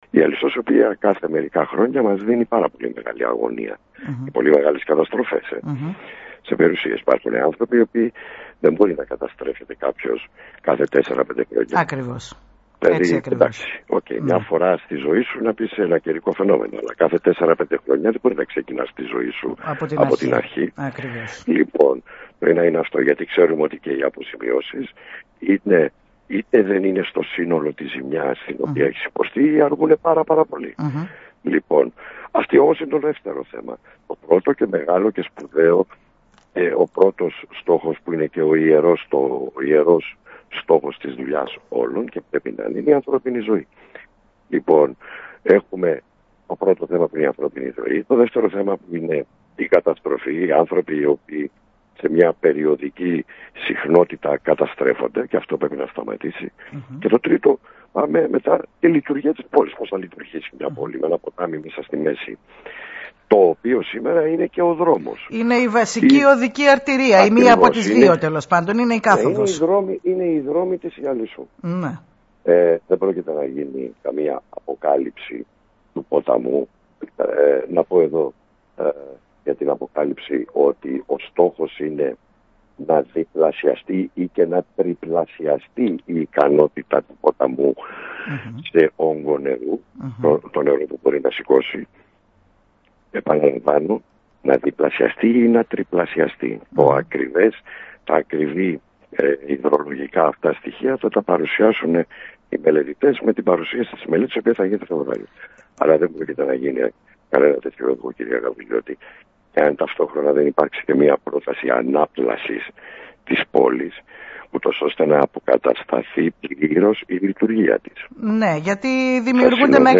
«Η μελέτη για το ποτάμι  θα συνοδεύεται από μελέτη ανάπλασης της περιοχής με στόχο να είναι πλήρως λειτουργική», δήλωσε σήμερα, στην ΕΡΤ Νοτίου Αιγαίου ο περιφερειάρχης, Γιώργος Χατζημάρκος και συνέστησε υπομονή μέχρι την παρουσίαση της μελέτης που θα γίνει μέσα στον Φεβρουάριο.